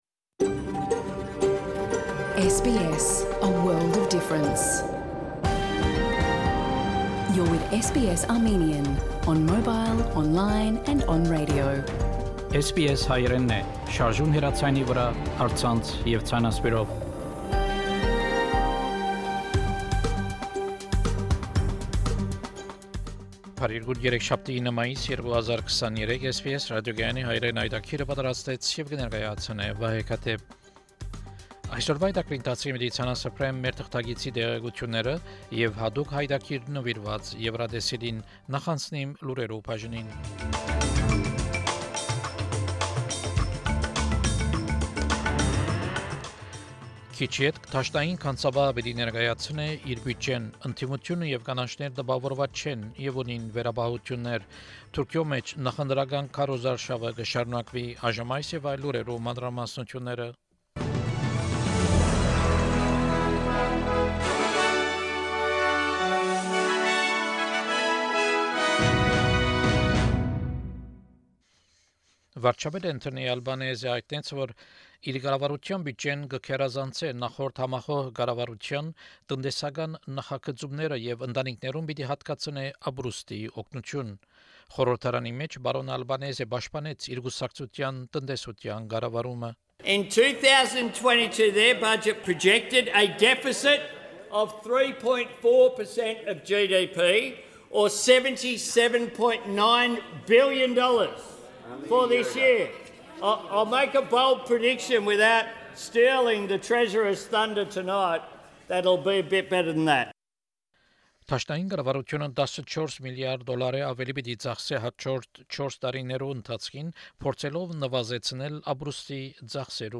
SBS Armenian news bulletin – 9 May 2023
SBS Armenian news bulletin from 9 May 2023 program.